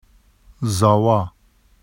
[zɑwɑ] n husband of daughter and husband of sister